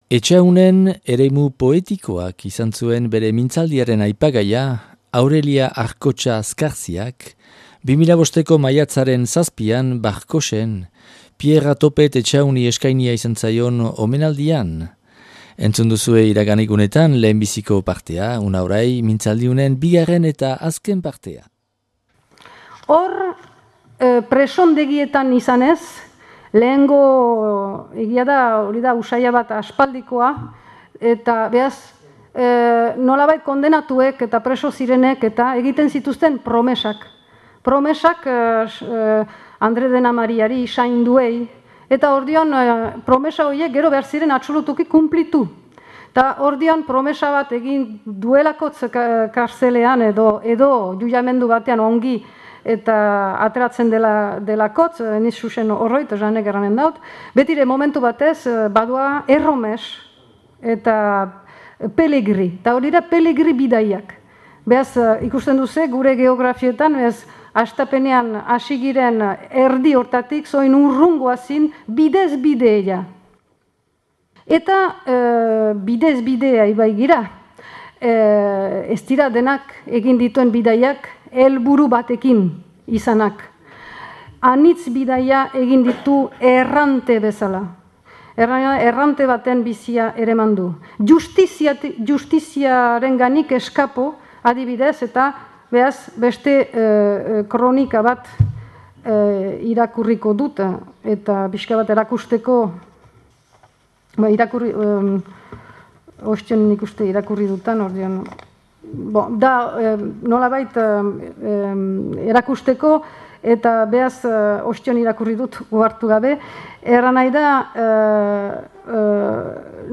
Piera Topet Etxahun omenadia Barkotxen 2005.